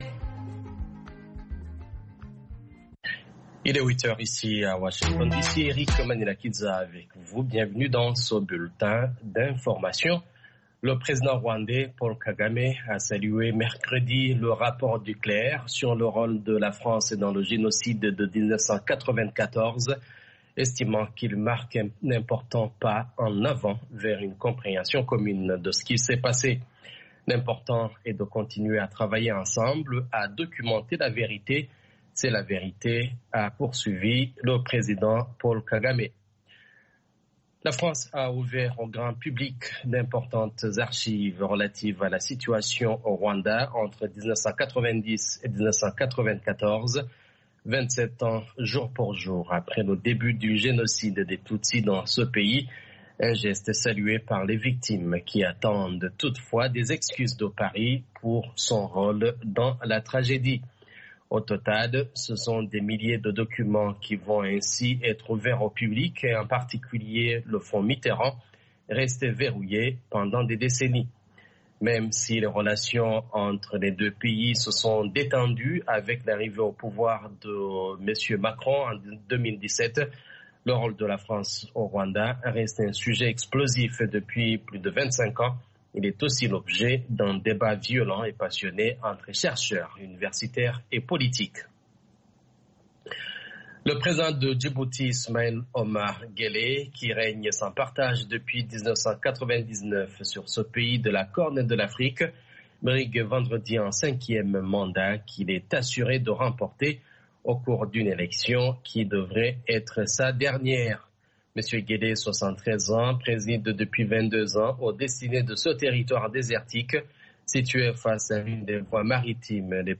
10 min News French